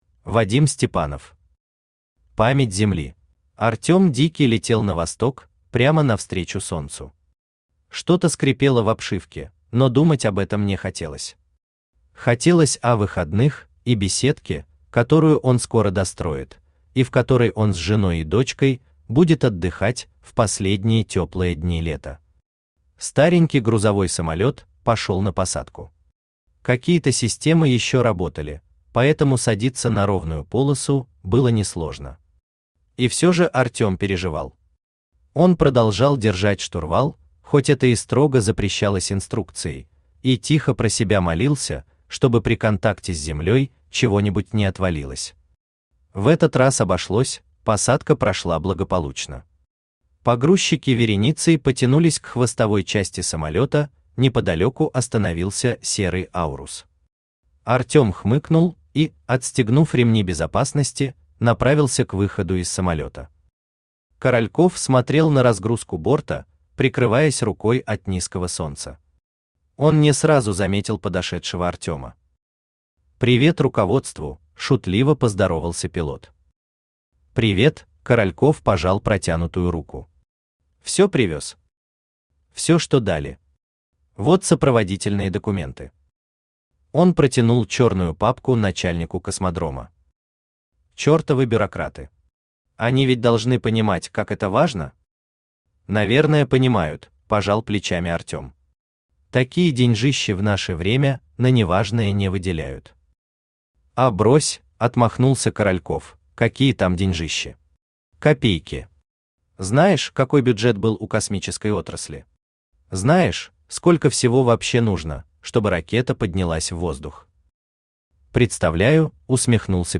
Aудиокнига Память Земли Автор Вадим Степанов Читает аудиокнигу Авточтец ЛитРес.